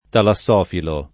[ tala SS0 filo ]